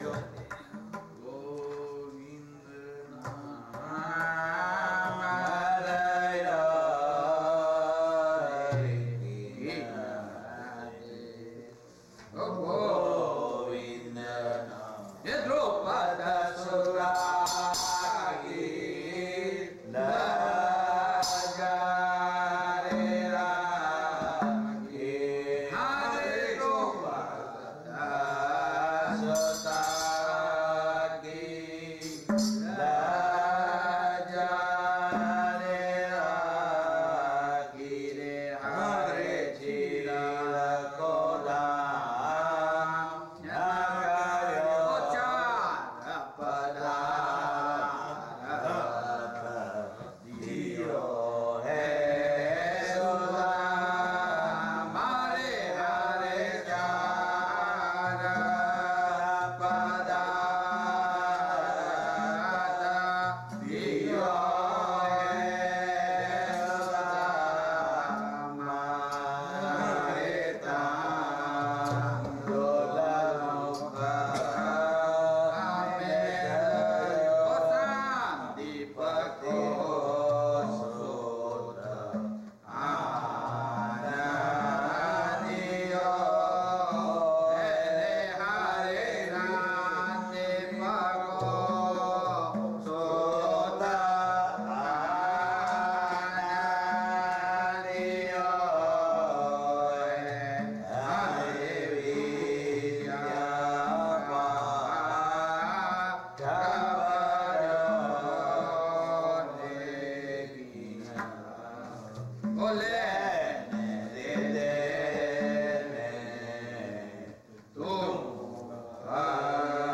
Kirtan - Ramkabir Mandir (2003)
rāg - sārang